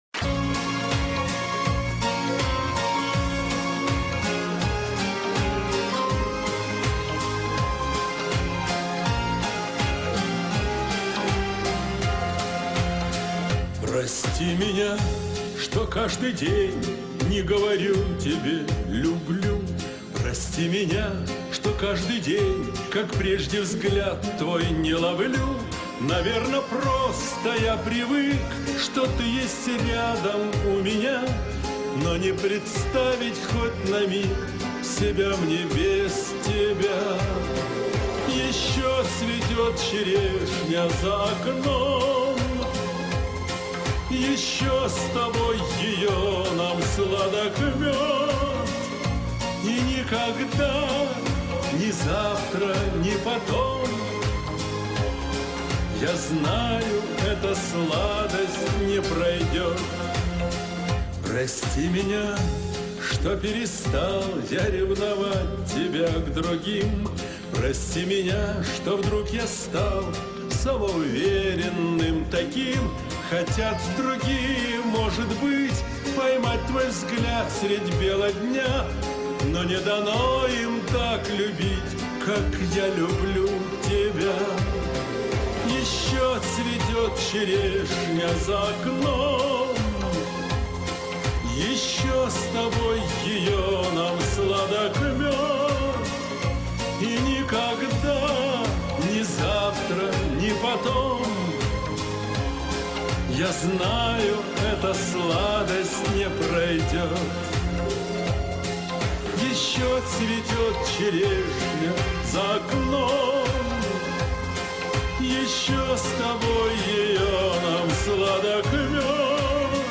он пел ее на юбилее